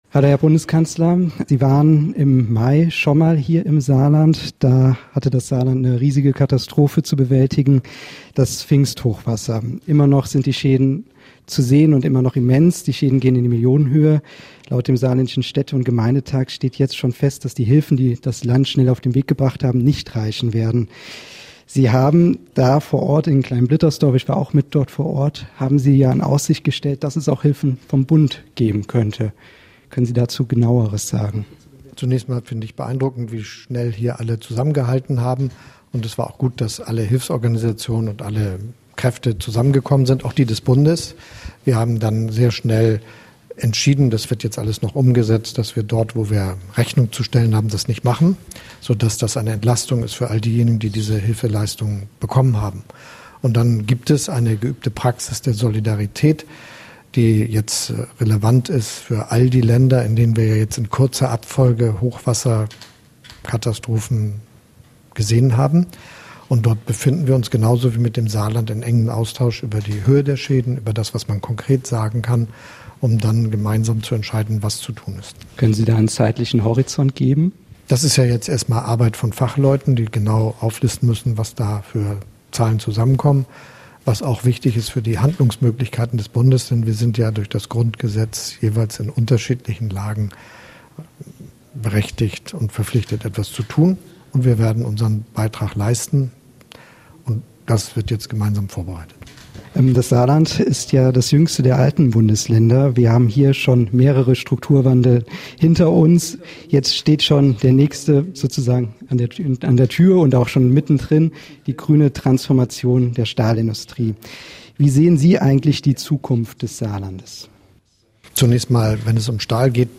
Bundeskanzler Olaf Scholz exklusiv im SALÜ-Interview
Gast: Bundeskanzler Olaf Scholz